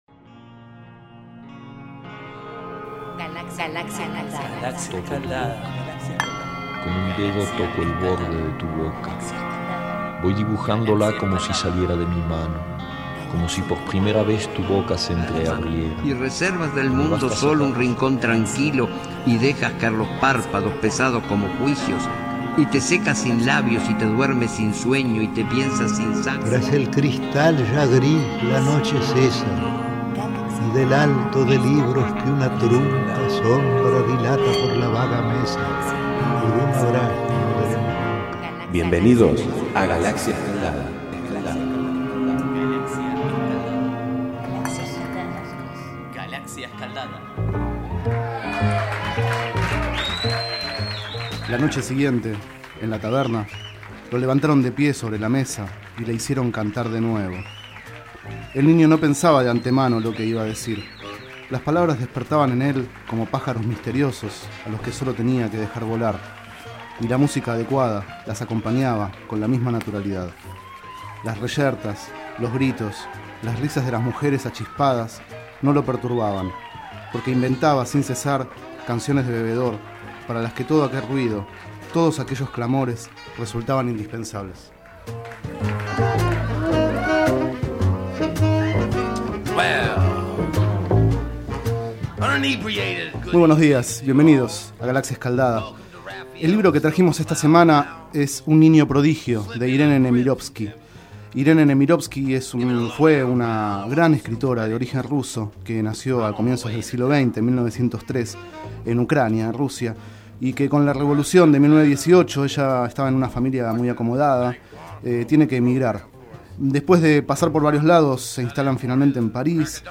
Este es el 33º micro radial, emitido en los programas Enredados, de la Red de Cultura de Boedo, y En Ayunas, el mañanero de Boedo, por FMBoedo, realizado el 22 de octubre de 2011, sobre el libro Un niño prodigio, de Irène Némirovsky.